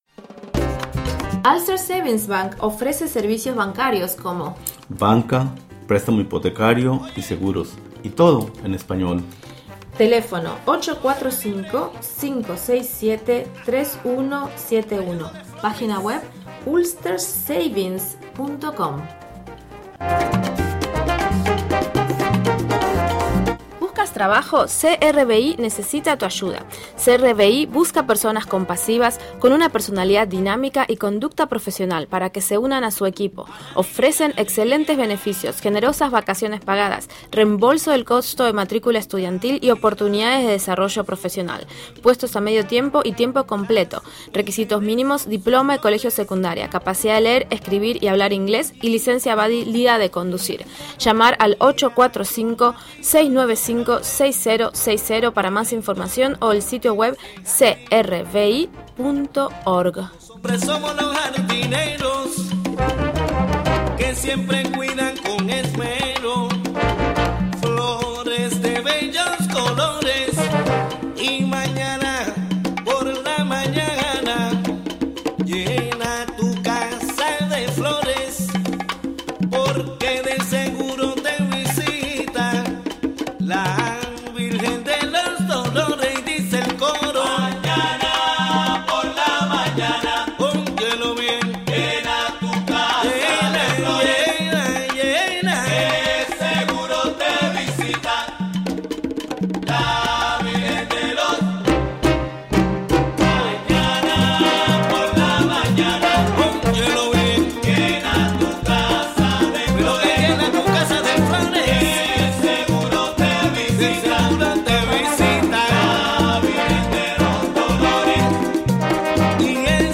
9am Un programa imperdible con noticias, entrevistas,...